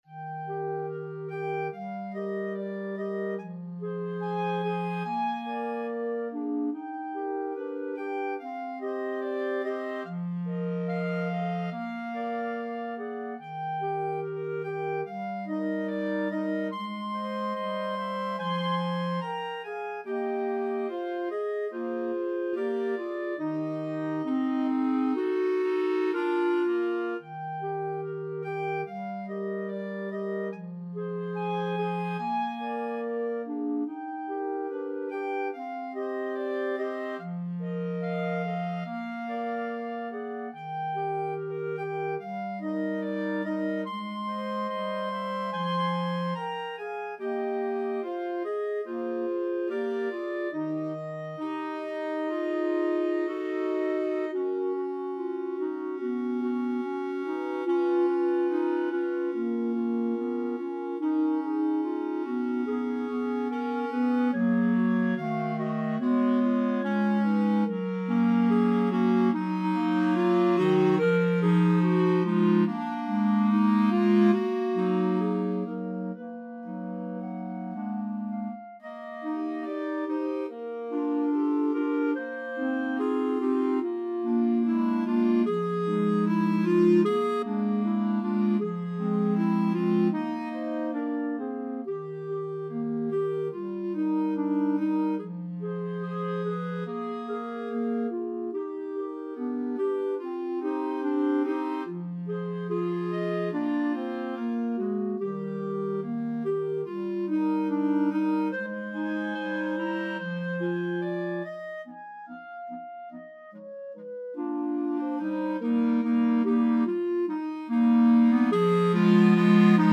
4/4 (View more 4/4 Music)
Eb major (Sounding Pitch) F major (Clarinet in Bb) (View more Eb major Music for Clarinet Quartet )
Andantino =72 (View more music marked Andantino)
Clarinet Quartet  (View more Intermediate Clarinet Quartet Music)
Classical (View more Classical Clarinet Quartet Music)